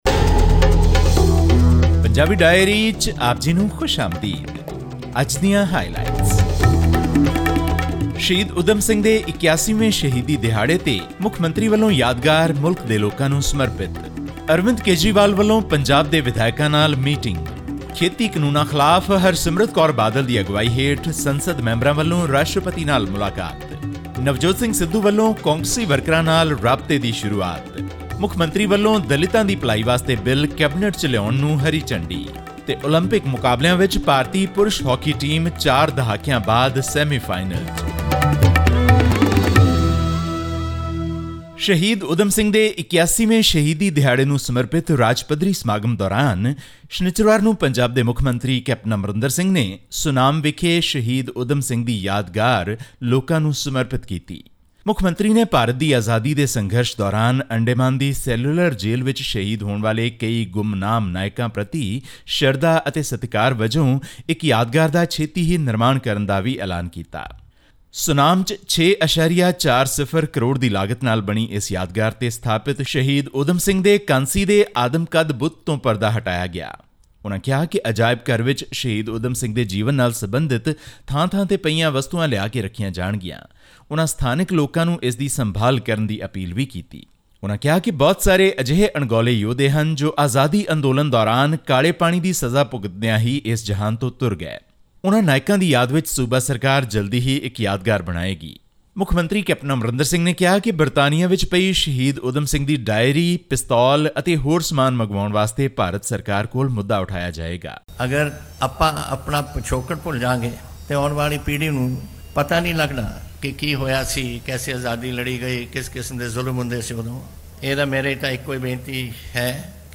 Punjab chief minister Capt Amarinder Singh inaugurated the Shaheed Udham Singh Memorial in Sunam on the occasion of the 82nd martyrdom day of the freedom fighter on 31 July. Tune into this podcast for a weekly news update from Punjab.
Click on the player at the top of the page to listen to the news bulletin in Punjabi.